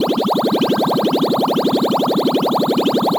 potion_bubbles_brewing_loop_02.wav